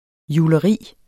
Udtale [ ˌjuːlʌˈʁiˀ ]